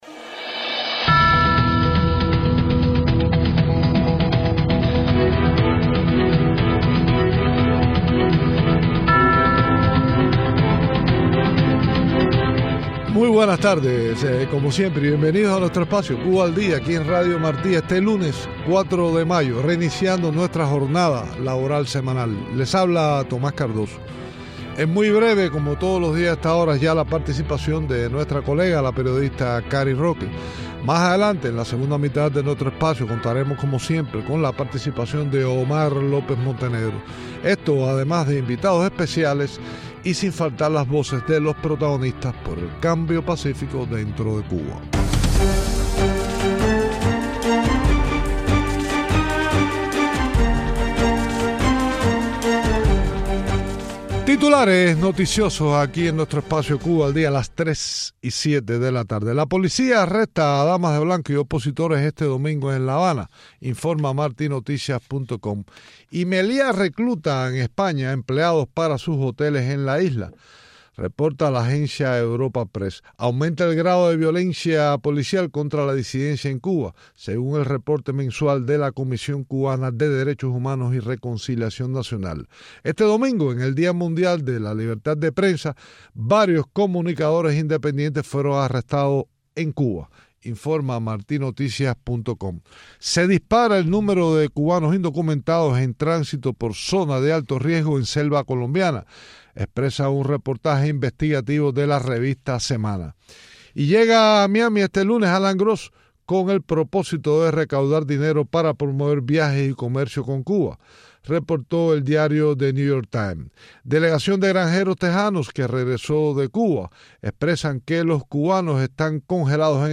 Entevistas